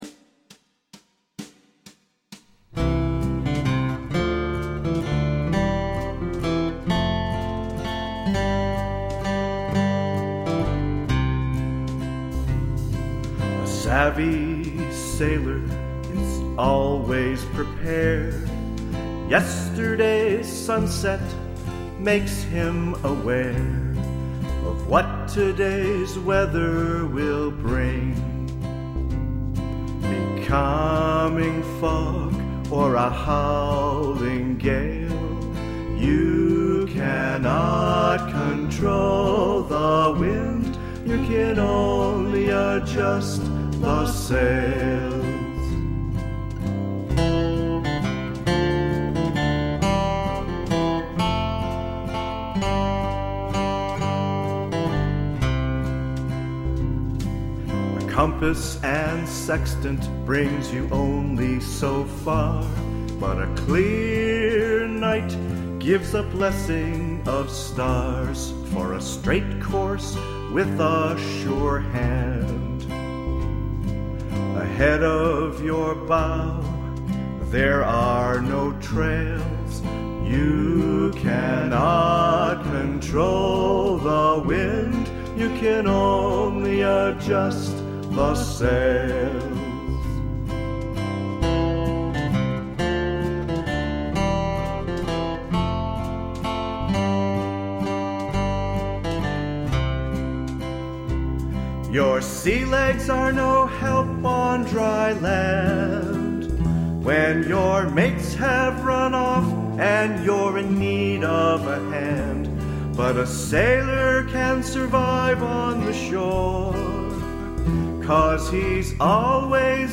A real rough recording.